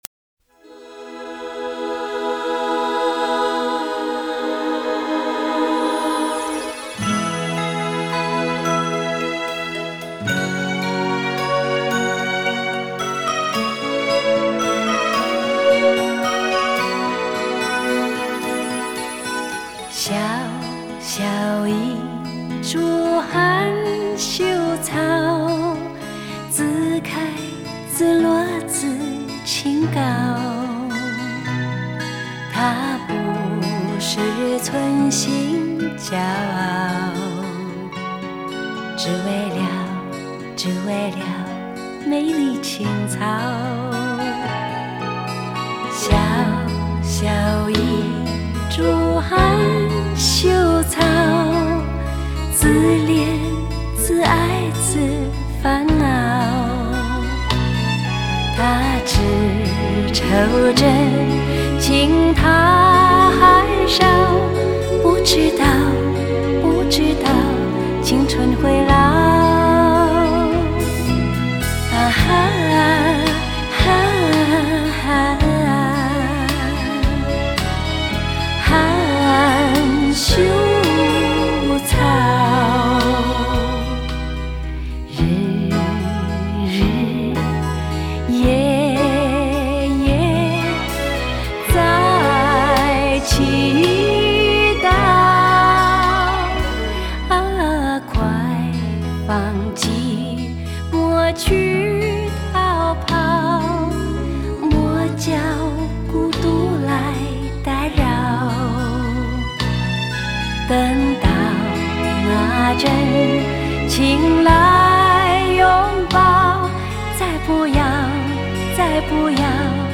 类别: 电音